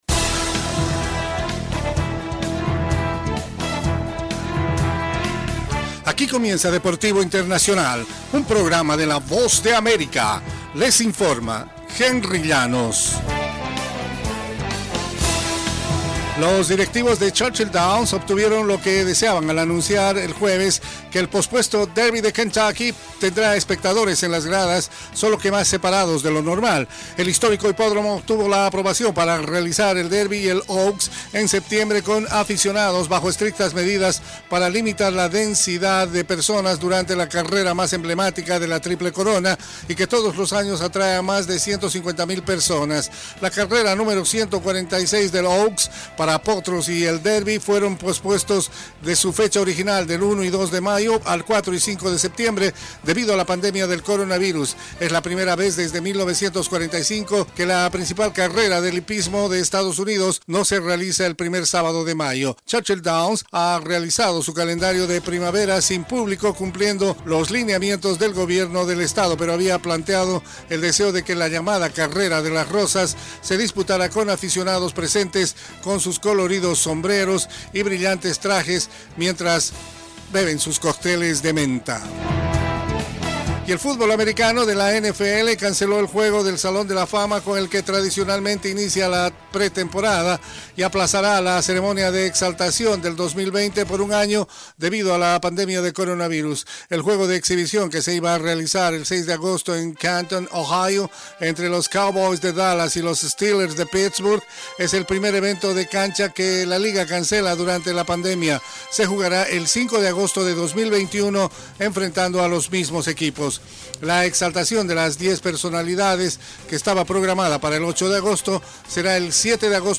Las noticias deportivas llegan desde los estudios de la Voz de América en la voz de